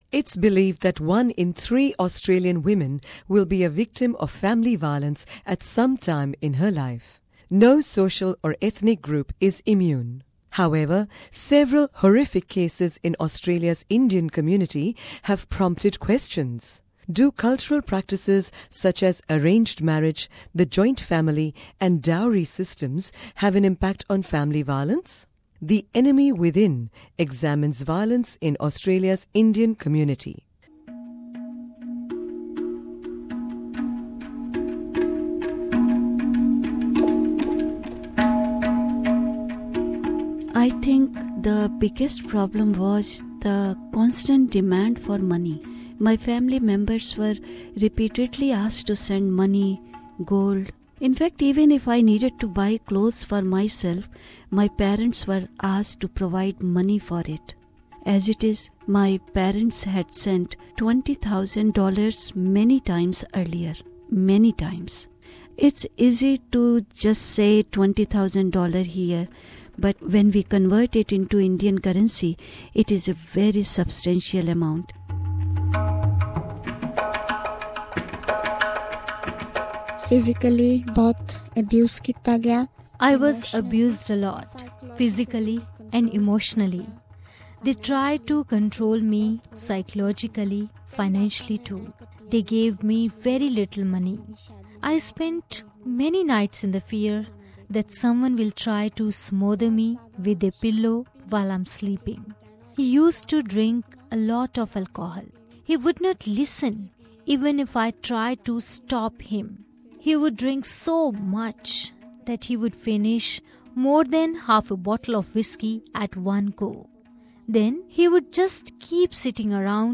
For legal reasons some names have been changed and some voices have been altered, so that the victims can't be identified.